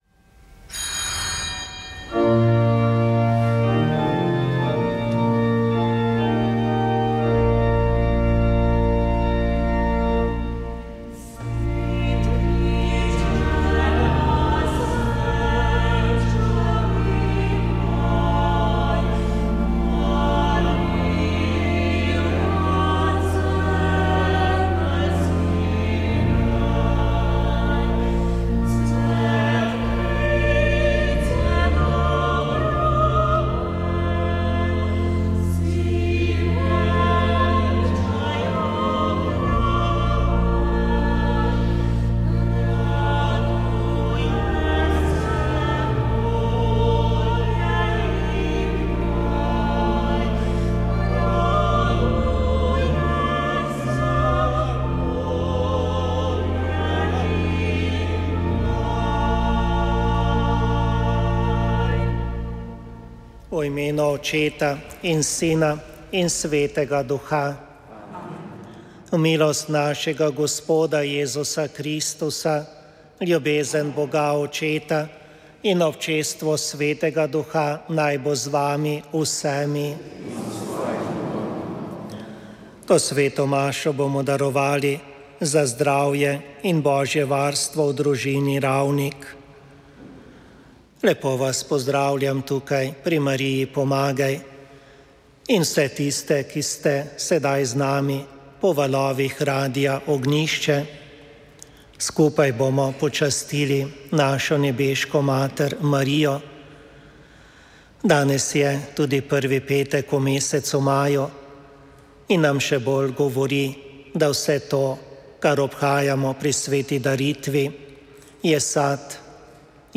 Sv. maša iz bazilike Marije Pomagaj na Brezjah 1. 5.